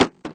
thud_and_bounce_3.ogg